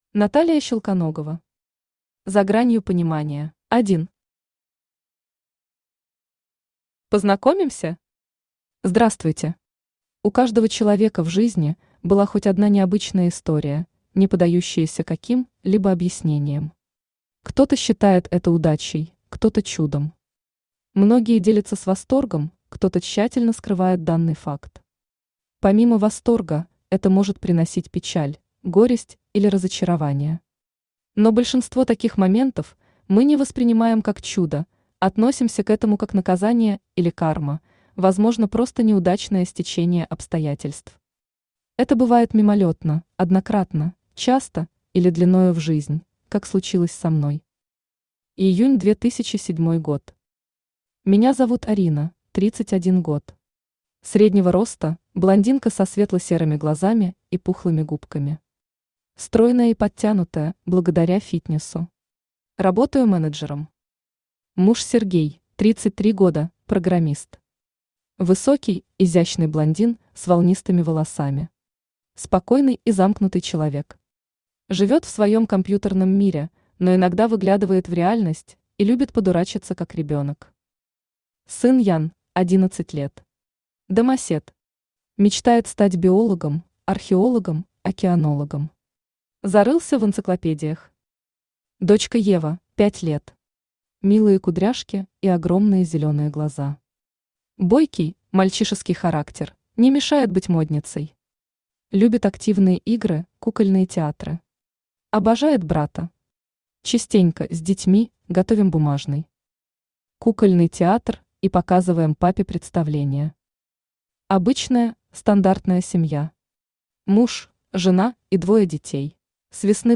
Аудиокнига За гранью понимания | Библиотека аудиокниг
Aудиокнига За гранью понимания Автор Наталия Владимировна Щелконогова Читает аудиокнигу Авточтец ЛитРес.